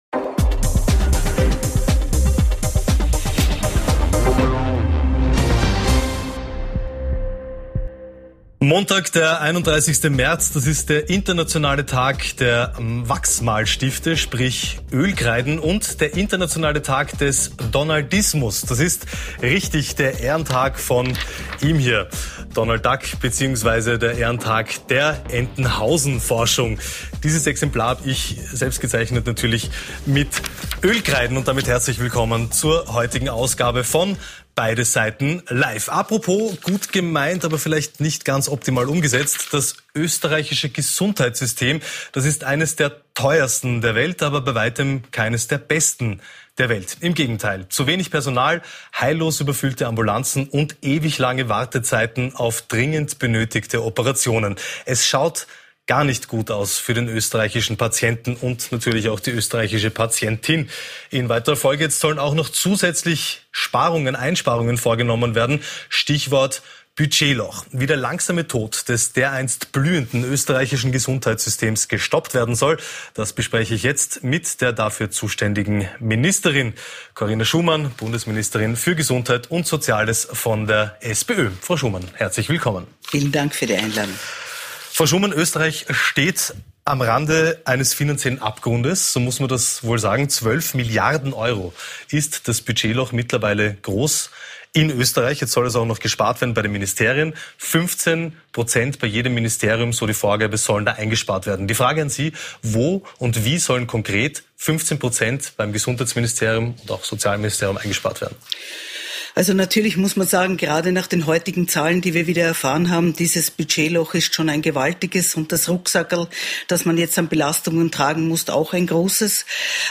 Das tägliche PULS 24 Primetime-Newsmagazin “Beide Seiten Live” beleuchtet in einer Doppelmoderation die wichtigsten Themen des Tages aus verschiedenen Perspektiven und bietet einen Überblick über aktuelle Ereignisse und endet mit einem Interview des Tages mit hochkarätigen Gästen.